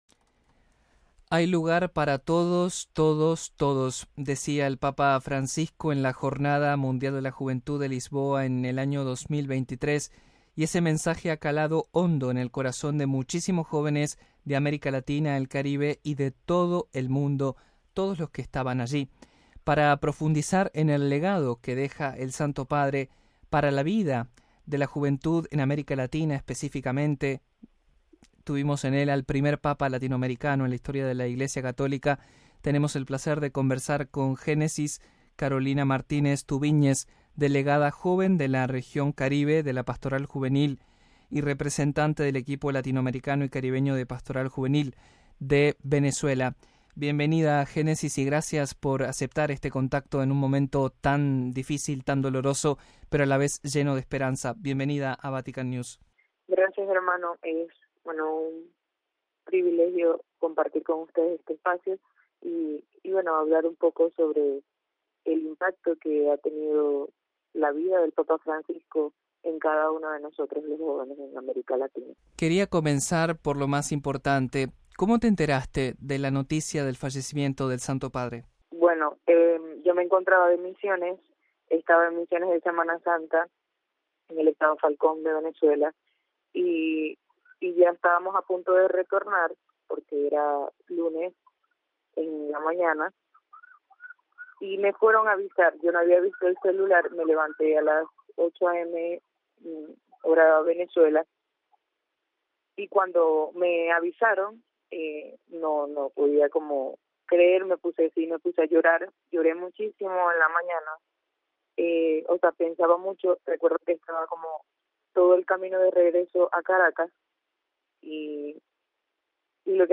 Listen to the interview with the representative of the Caribbean region in Latin America and the Caribbean pastry region